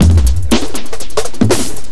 ELECTRO 08-R.wav